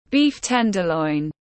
Thịt thăn bò tiếng anh gọi là beef tenderloin, phiên âm tiếng anh đọc là /ˈbiːf ˈten.də.lɔɪn/
Beef tenderloin /ˈbiːf ˈten.də.lɔɪn/